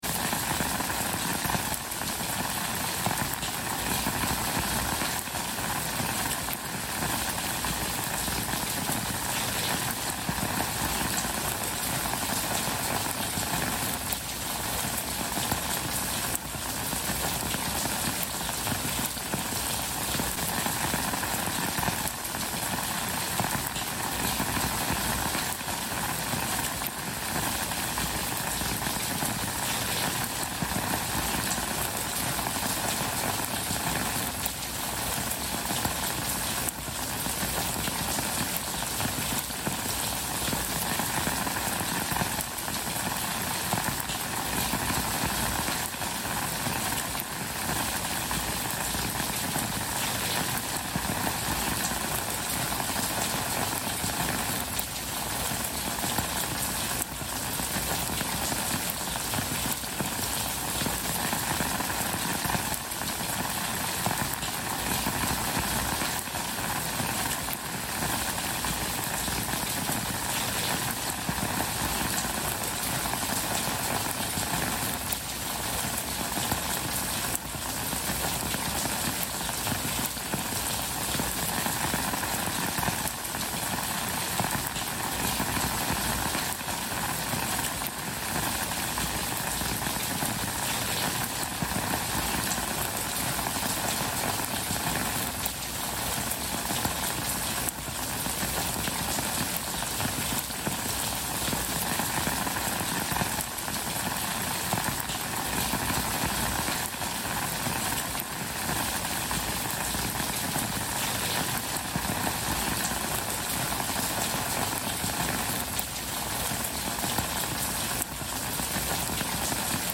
Rain on the Sidewalk Glow: Gentle Urban Rain for Calm (1 Hour)
Before you press play, you should know this: all advertisements for Rain Sounds, Rain To Sleep, Rainy Day, Raining Forest, Rainy Noise are placed gently at the very beginning of each episode.